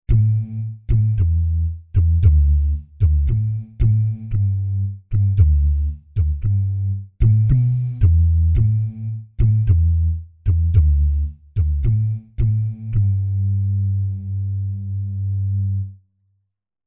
Index of /90_sSampleCDs/Spectrasonics Vocal Planet CD6 - Groove Control/Soundfinder/VP MVP Soundfinder Files/ 4. Jazz/2. Jazz Bass Multisamples